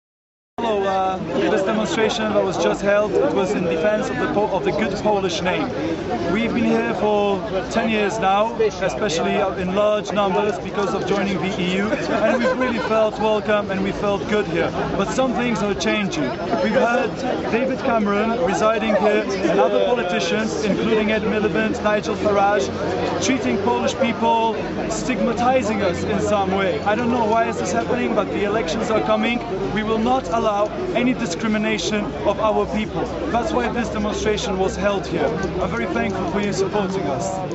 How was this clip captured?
Patriae Fidelis- Polish demonstration outside10 Downing street